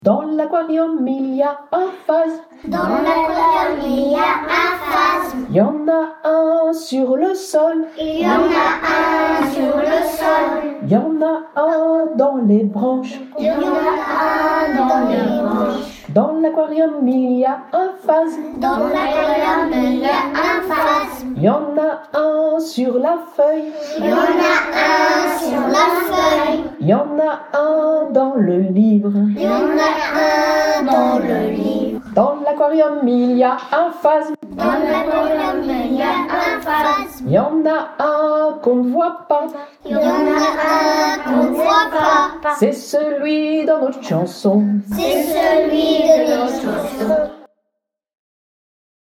Vint ensuite le temps de la pratique…Piano, maracas, wooden agogo, les instruments de musique étaient aussi de la partie ! Curieux et audacieux, les enfants n’ont pas hésité à manipuler les instruments et à tester les percussions corporelles.